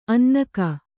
♪ annaka